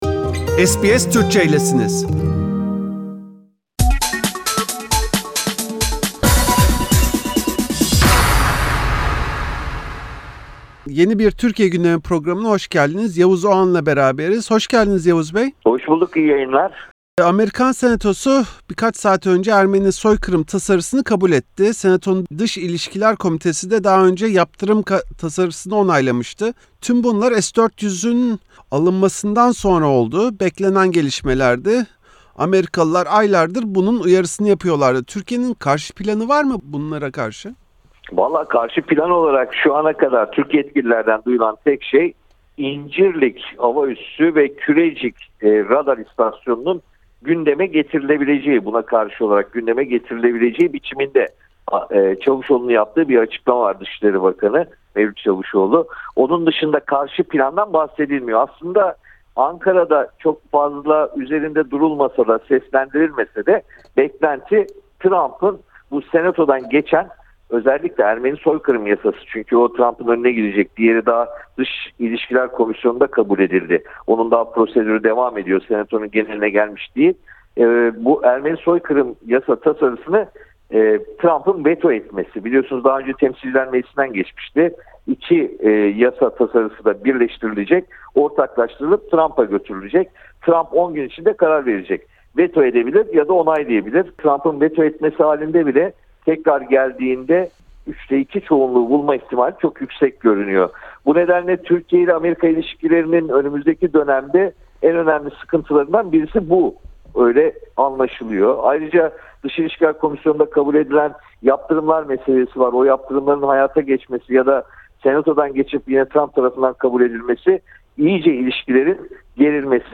Gazeteci Yavuz Oğhan’la, Amerika Birleşik Devletleri Senatosunca kabul edilen Ermeni soykırımı tasarısı, Ahmet Davutoğlu’nun Gelecek Partisi’ni ve çılgın proje Kanal istanbul’u konuştuk.